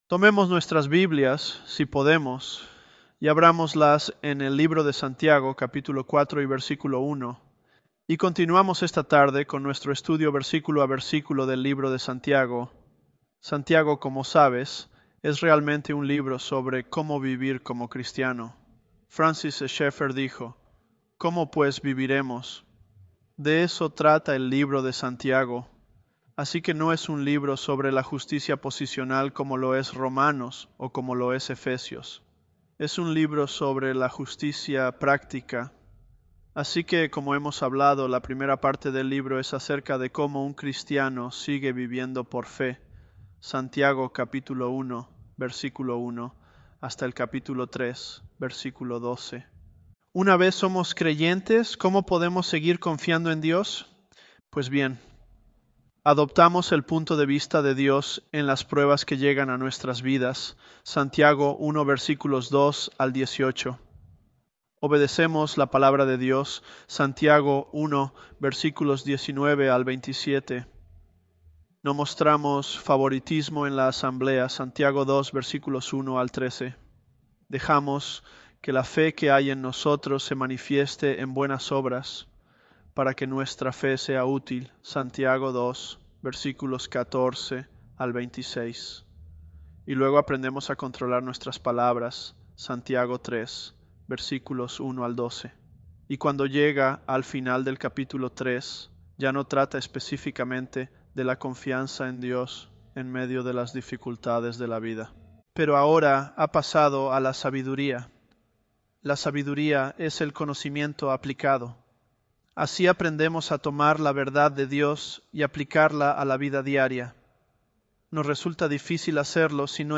Sermons
ElevenLabs_James020.mp3